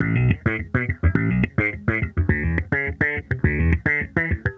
Index of /musicradar/sampled-funk-soul-samples/105bpm/Bass
SSF_JBassProc2_105B.wav